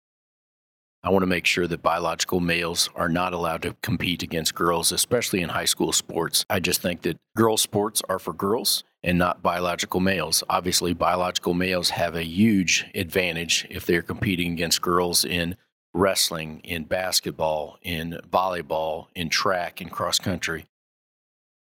1. Senator Hoskins says Senate Bill 781 is legislation that seeks to Establishes the “Save Women’s Sports Act.”